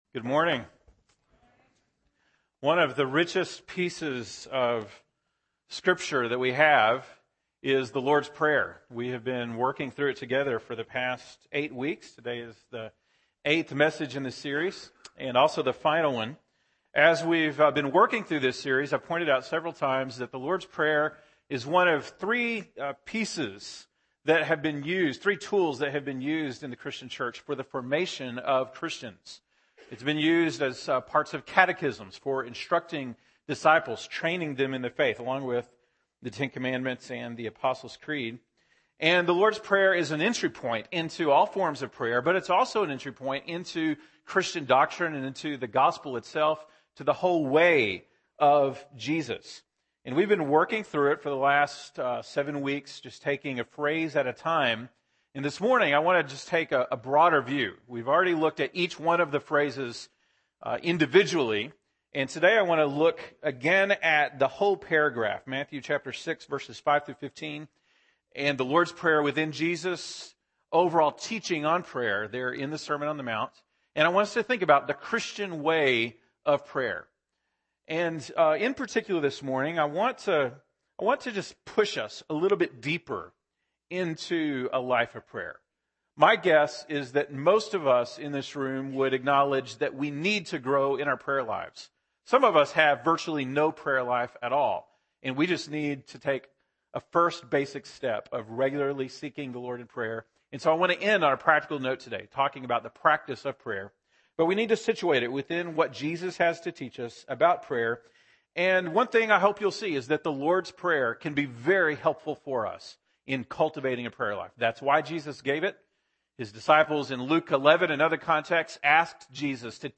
November 23, 2014 (Sunday Morning)